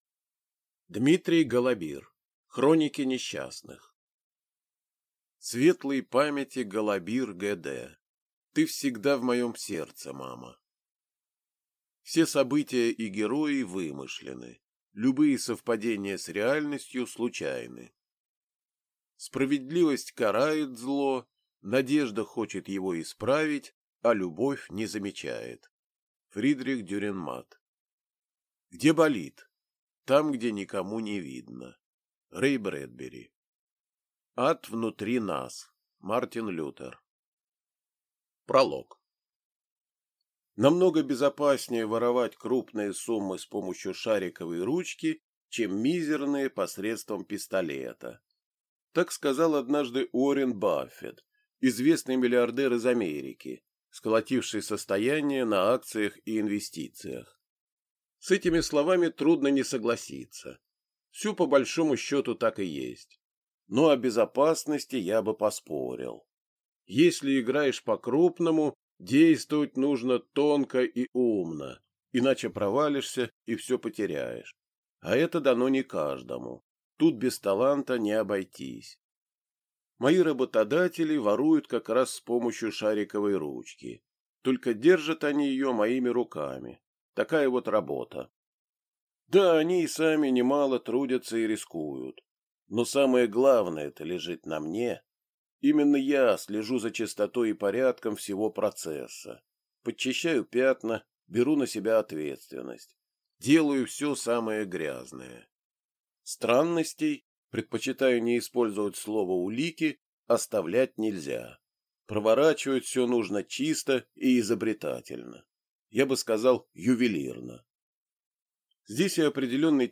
Аудиокнига Хроники несчастных | Библиотека аудиокниг